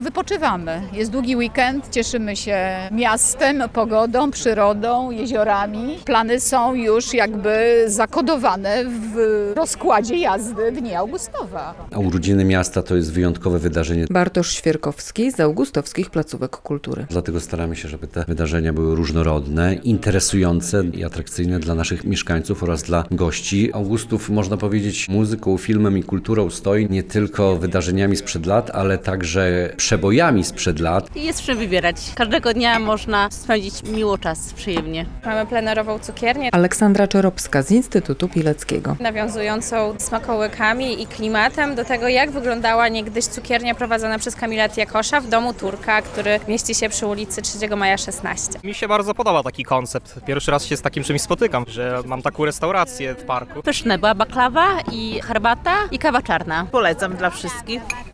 Trwają czterodniowe Dni Augustowa - relacja